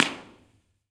Humanities & Social Sciences Courtyard, University of California, San Diego
Concrete, trees.
Download this impulse response (right click and “save as”)